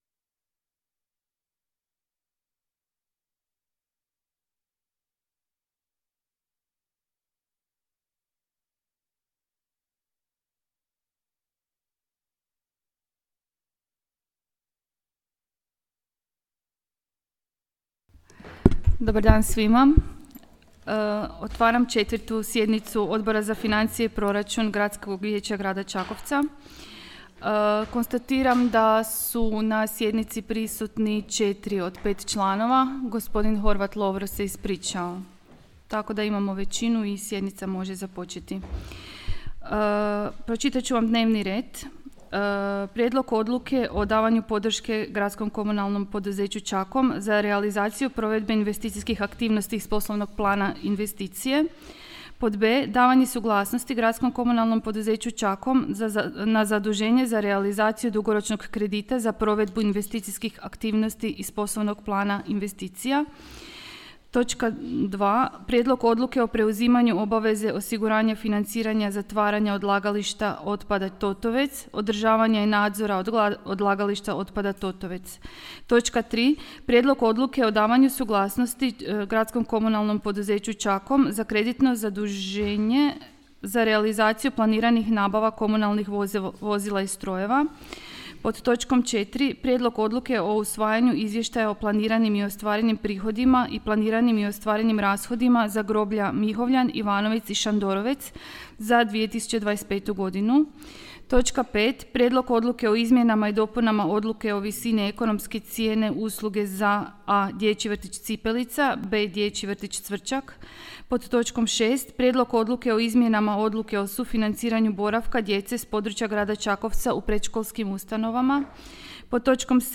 Obavještavam Vas da će se 4. sjednica Odbora za financije i proračun Gradskog vijeća Grada Čakovca održati dana 24. veljače 2026. (utorak), u 10:30 sati, u prostorijama Uprave Grada Čakovca.